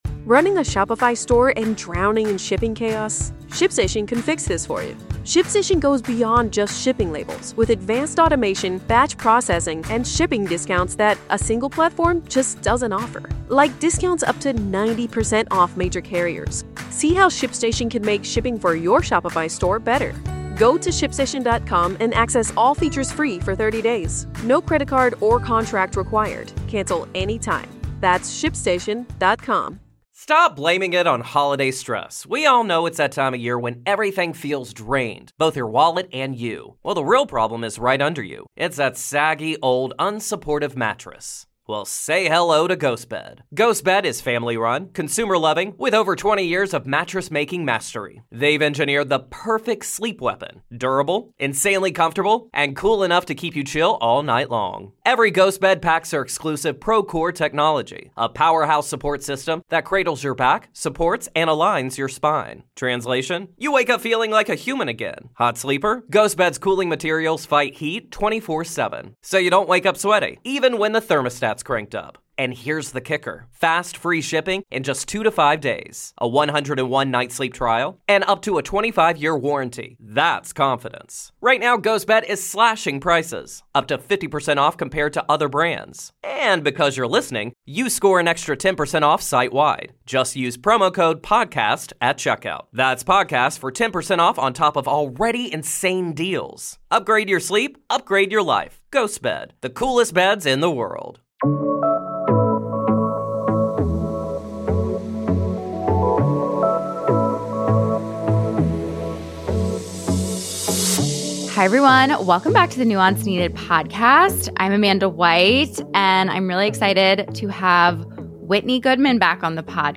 In conversation, we discuss: Why estrangement has become a more common topic in online mental health spaces. The differences between setting boundaries, creating distance, and choosing no contact. How estrangement can bring both relief and grief — sometimes at the same time.